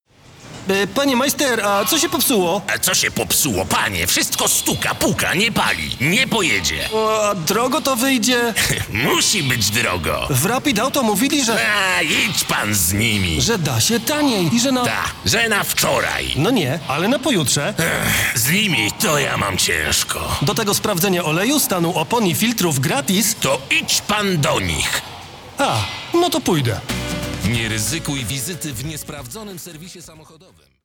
Male 30-50 lat
Demo lektorskie
Spot reklamowy - scenka